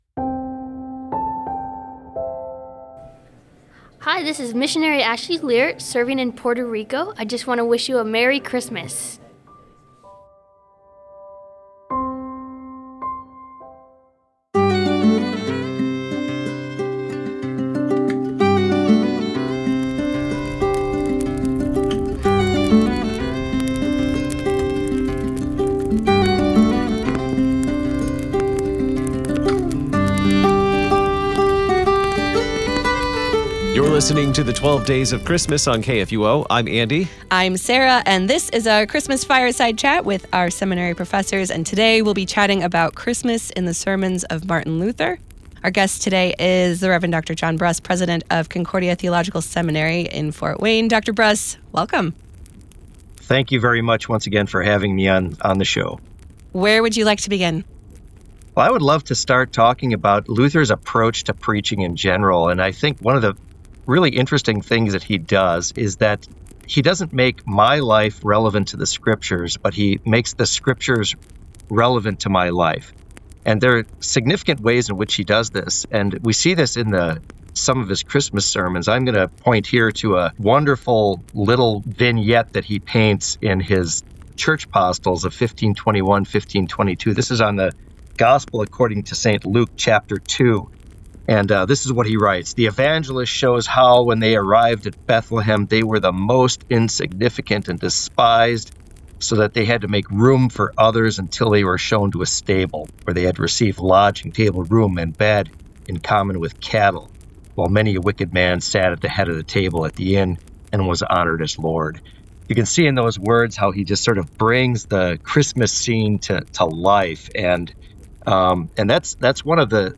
Fireside Chat